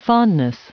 Prononciation du mot fondness en anglais (fichier audio)
Prononciation du mot : fondness